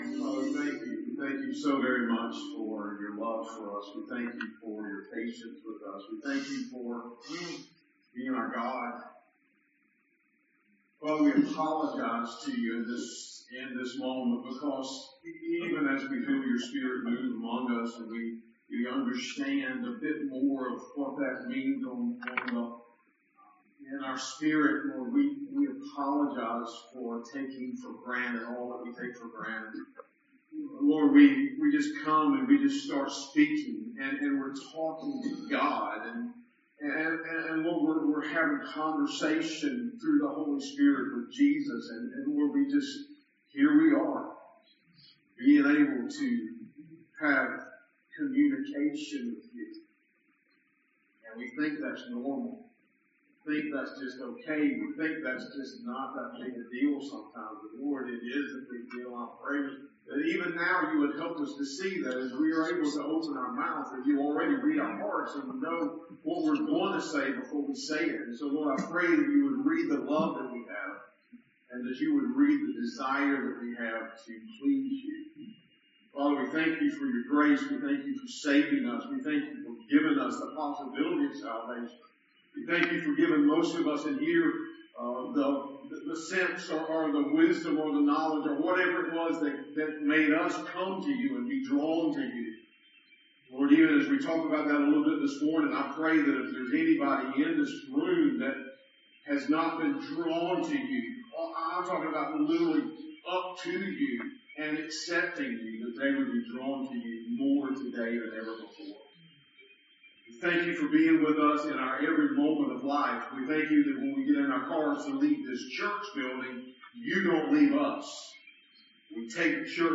Sermons | Lincoln Baptist Church